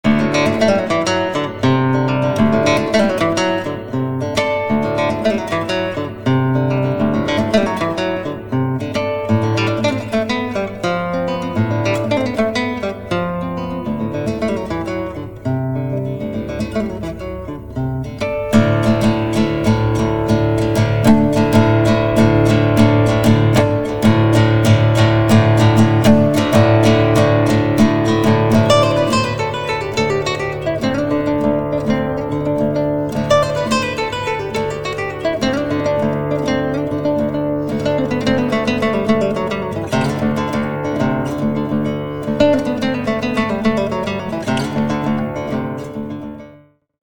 Solo Instrument gitarre.mp3 Aufnahme in einer Aula mit sehr guten akustischen Eigenschaften. Laufzeit-Stereophonie Verfahren mit St�tzmikrofon. Nachbearbeitung ohne k�nstliche Hall-Effekte.
gitarre.mp3